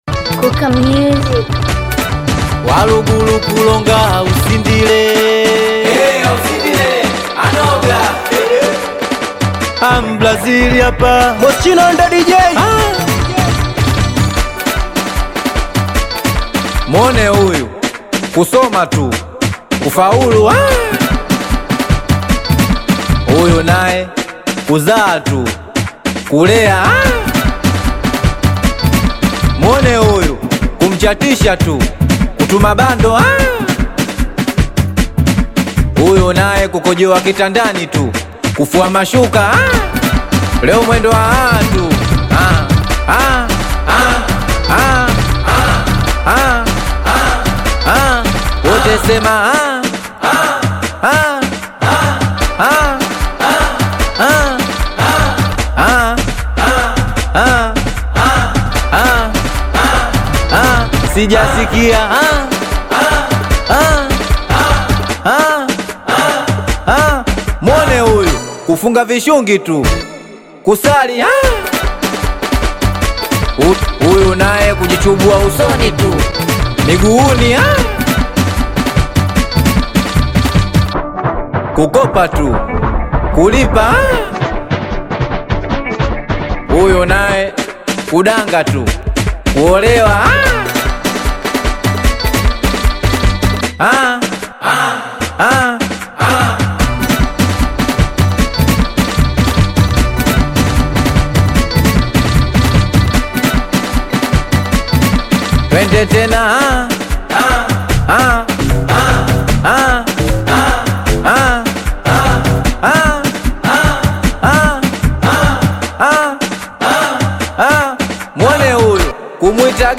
Singeli music track
Tanzanian Bongo Flava artist
Singeli song